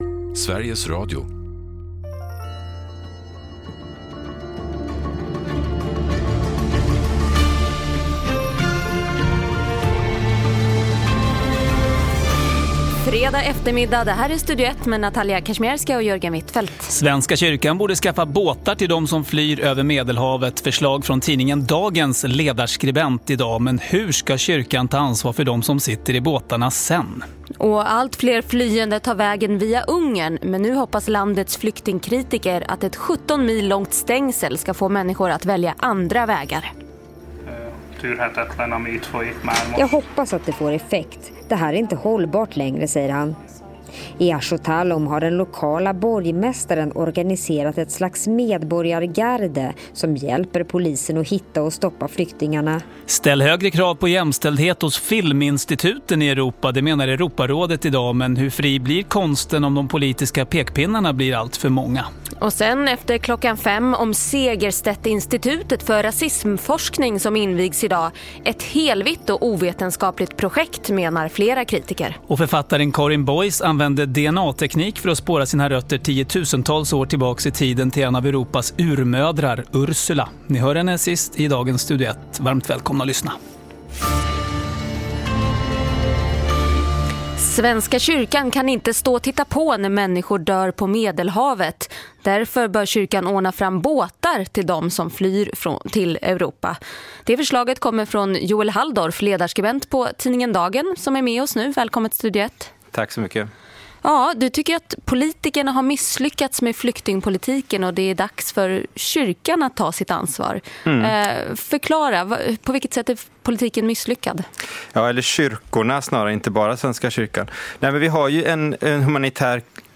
Sveriges Radio, Studio Ett: Interview om myter om køn i dansk kultur og folkeskole